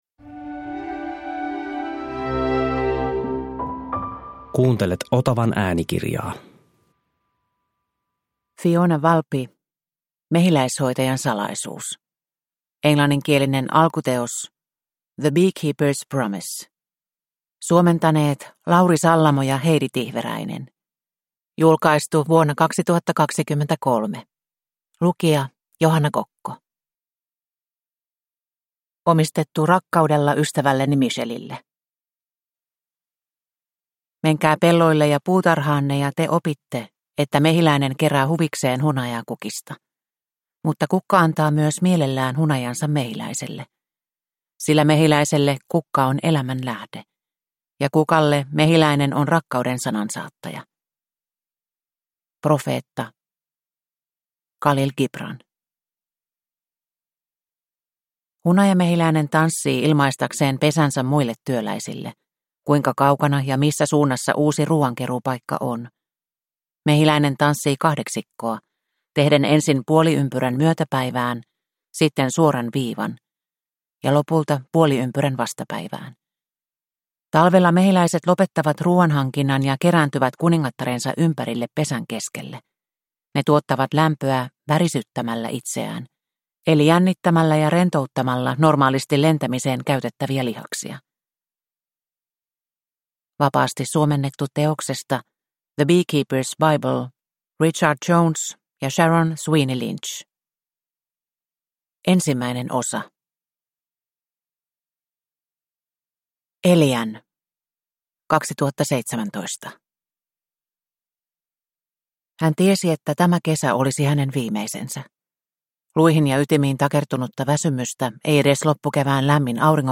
Mehiläishoitajan salaisuus (ljudbok) av Fiona Valpy